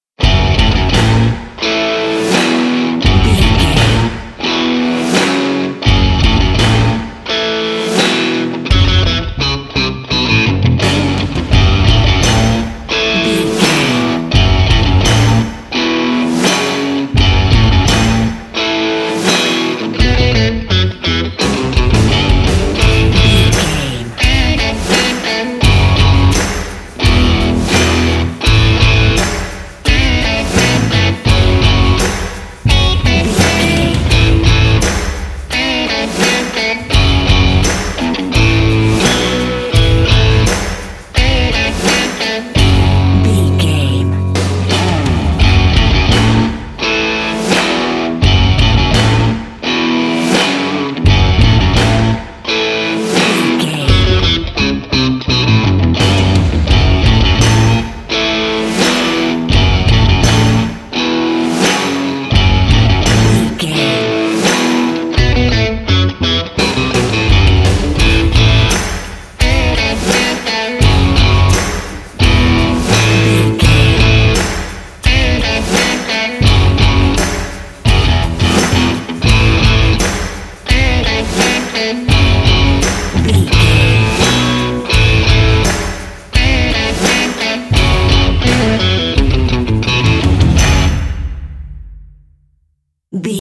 Epic / Action
Uplifting
Aeolian/Minor
F♯
bass guitar
electric guitar
drum machine
aggressive
driving
energetic
heavy